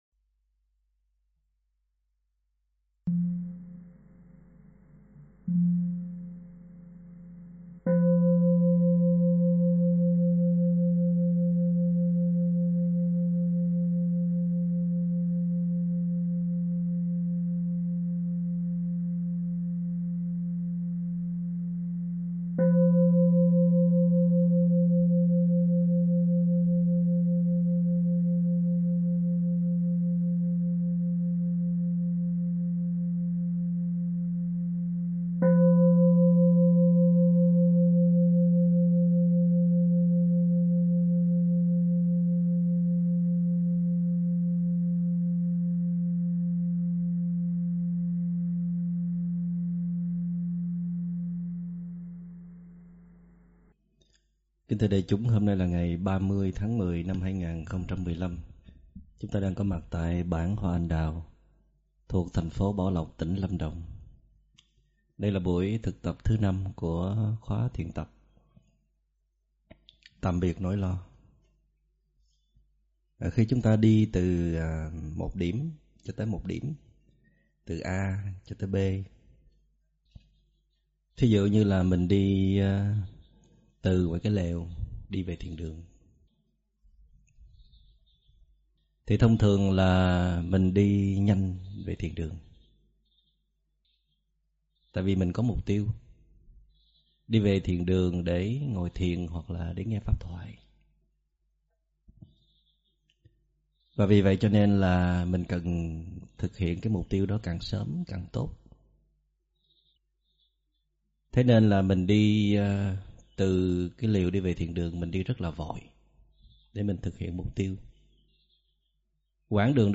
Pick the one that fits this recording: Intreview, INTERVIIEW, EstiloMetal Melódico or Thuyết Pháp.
Thuyết Pháp